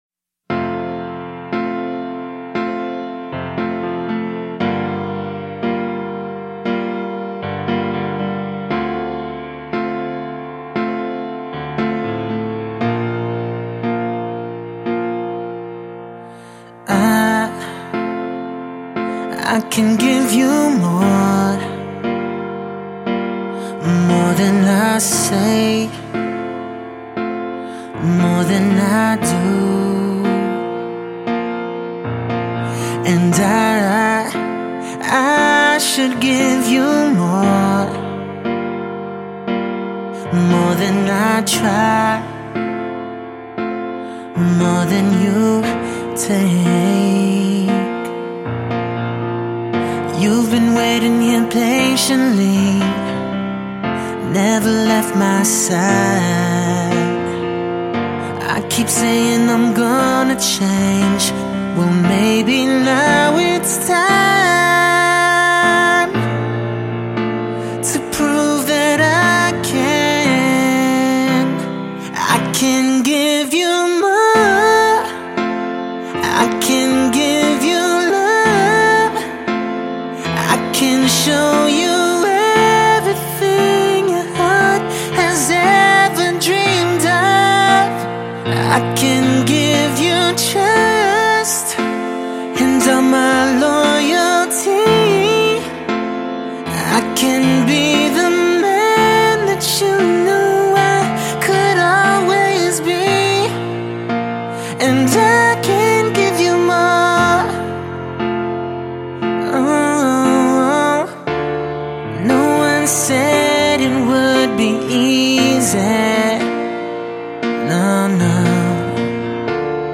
他唱出的歌却总是委婉悠长，他的声音清澈细腻，颇有女声味道